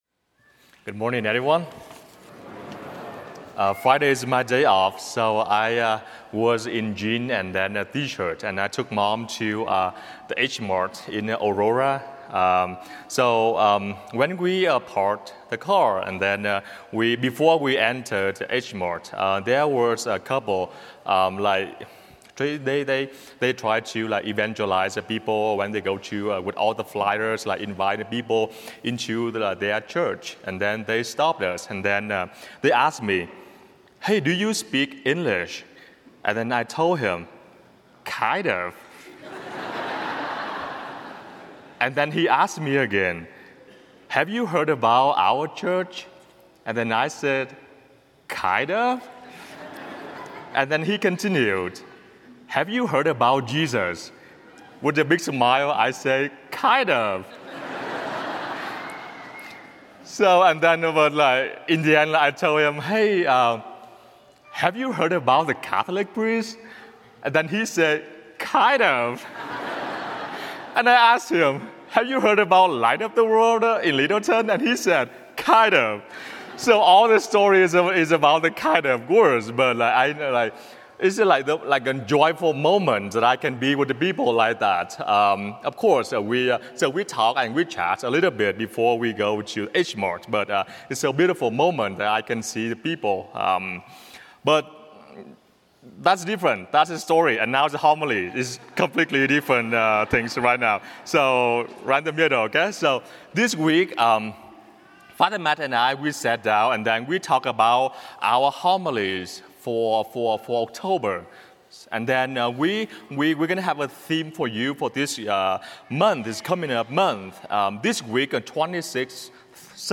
01 Oct 26th SOT Homily